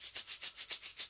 发现 " 鸡蛋振动器08
描述：Shaker打击乐器自制
Tag: 振动筛 打击乐 国产